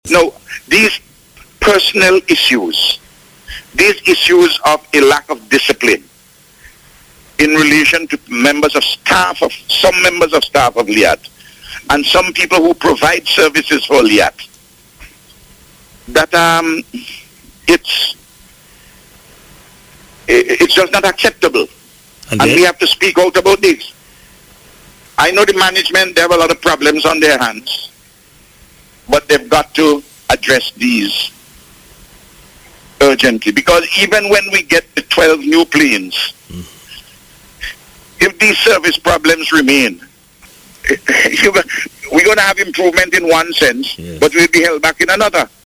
He telephoned the radio programme on Monday after a caller complained about LIAT service.